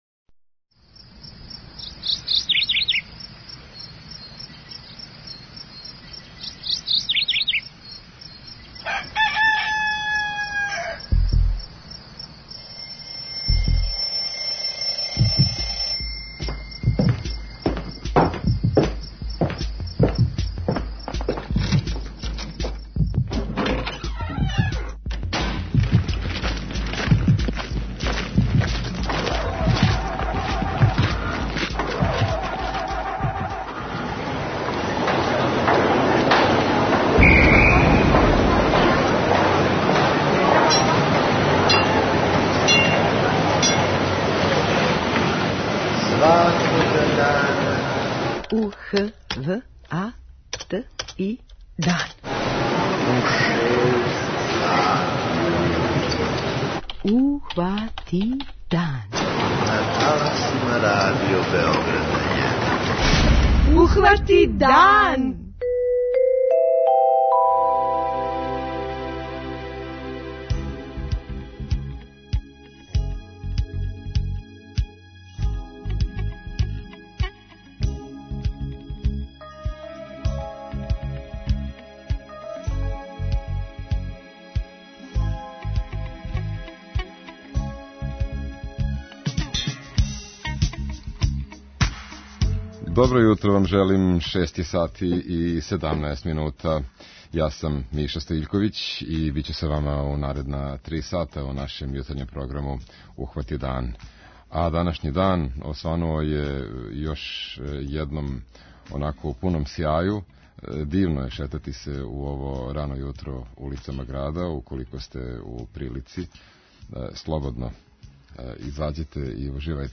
06:30 Догодило се на данашњи дан, 07:00 Вести, 07:05 Добро јутро децо, 08:00 Вести, 08:05 Српски на српском, 08:15 Гост јутра 08:50 Каменчићи у ципели
преузми : 29.54 MB Ухвати дан Autor: Група аутора Јутарњи програм Радио Београда 1!